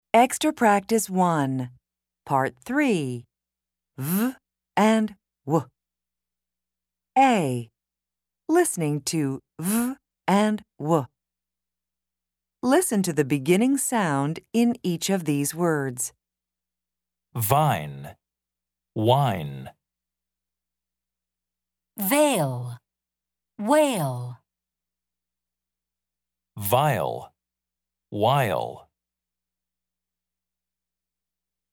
Pronunciation and Listening Comprehension in North American English
American English